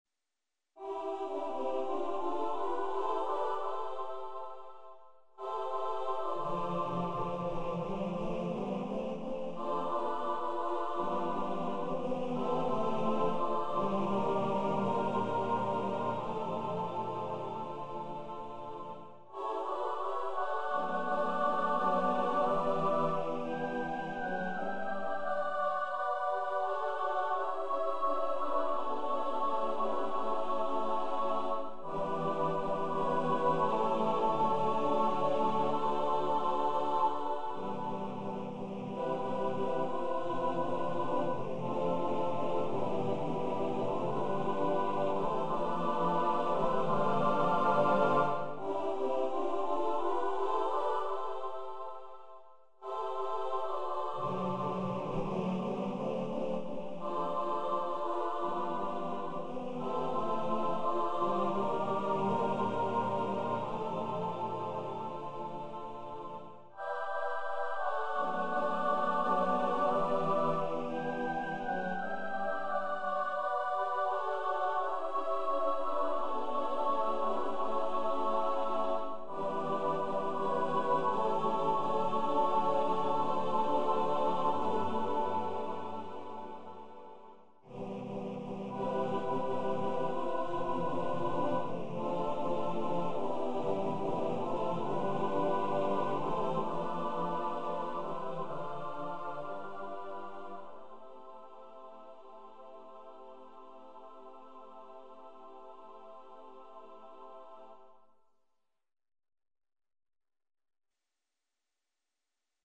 SSAATTBB Choir
Composer's Demo